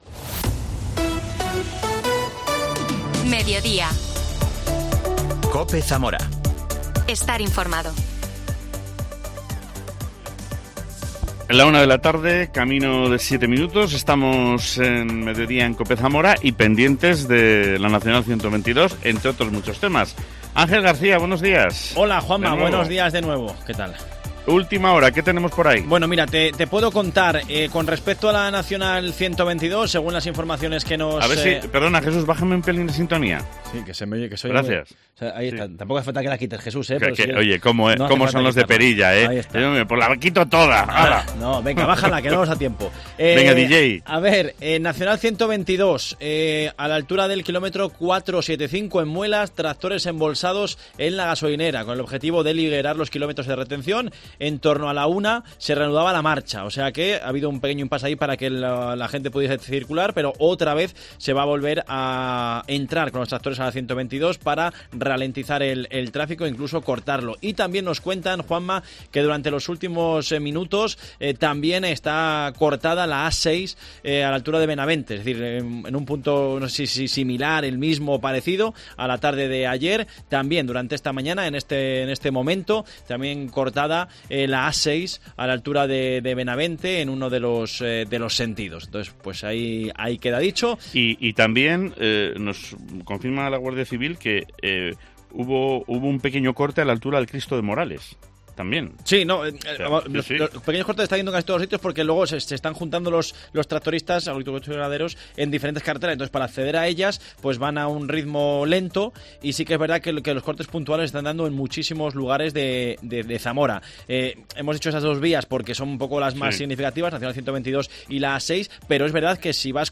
Fernando González, alcalde de San Vicente de la Cabeza, participa en la concentración de tractores que ha cortado la N-122 a la altura de Cerezal de Aliste.